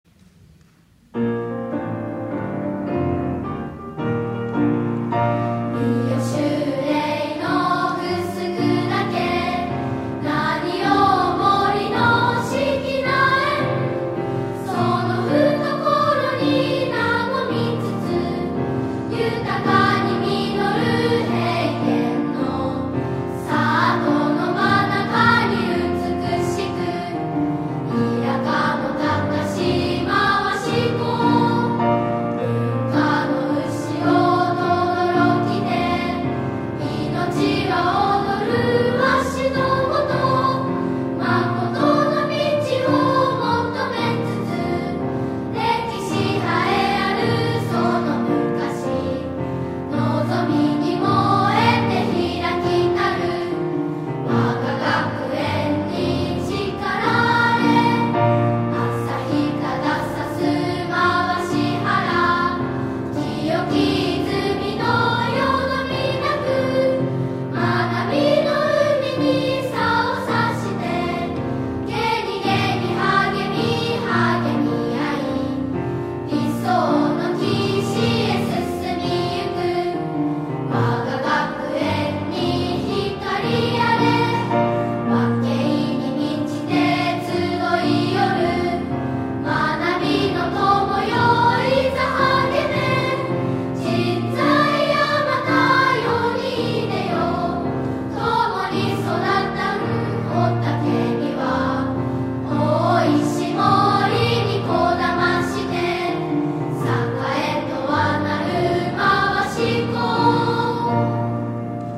校歌・沿革・教育目標 - 真和志小学校